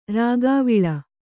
♪ rāgāviḷa